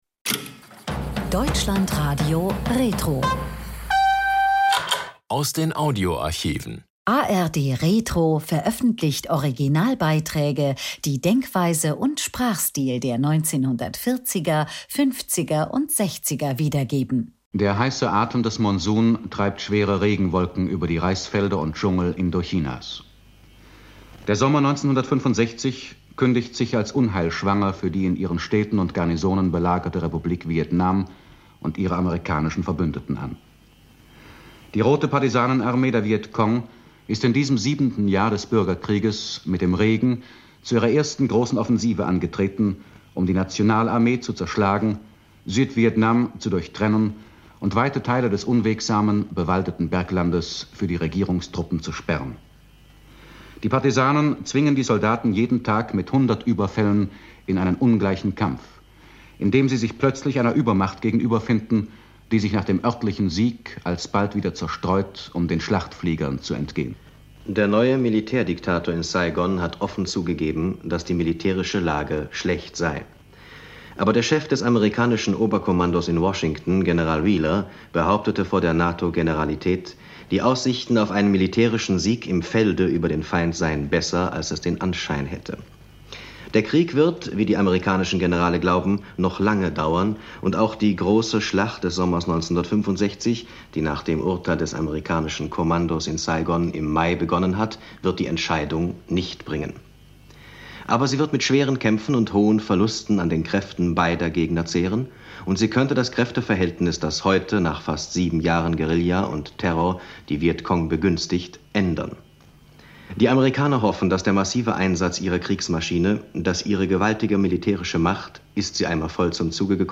Lothar Rühl berichtet für den RIAS über die Situation in Vietnam.